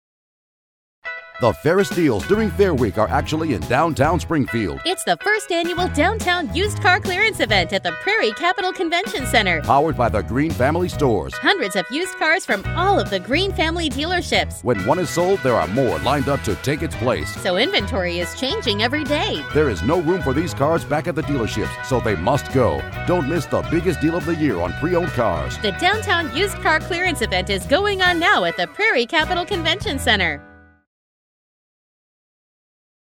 Adding a second (or third... or fourth!) voice to your spot creates depth and drama!
Family spot with SFX and Announcer finish. Music: Royalty Free-Pop Flight-36